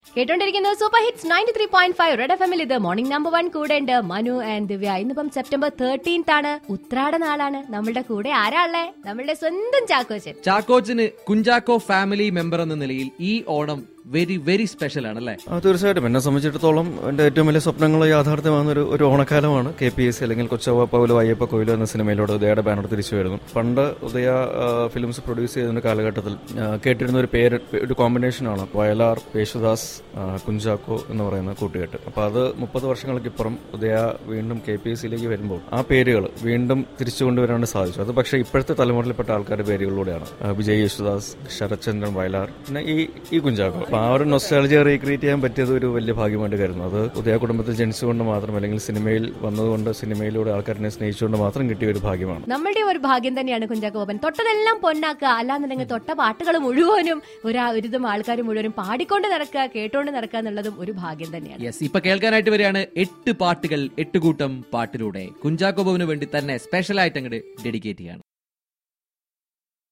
INTERVIEW WITH CINE ACTOR KUNCHAKO BOBAN.